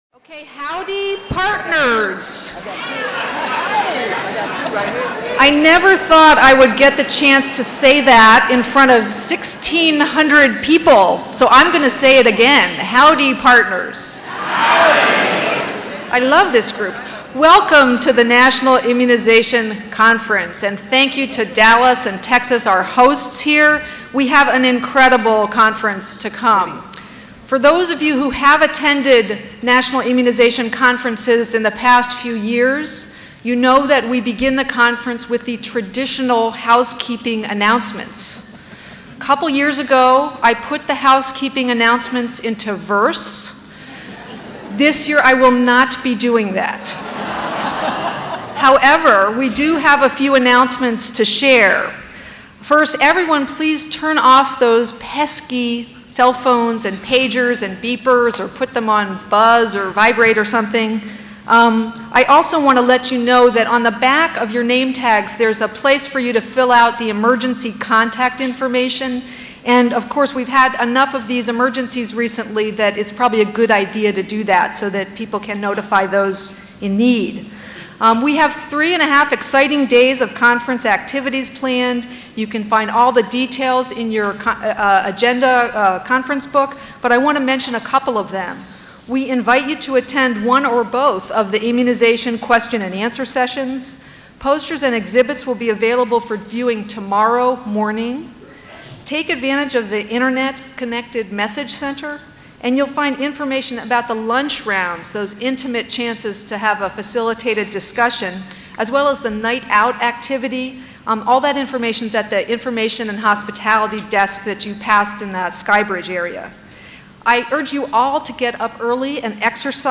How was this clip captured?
P1 Opening Plenary and Awards Dallas Ballroom BCD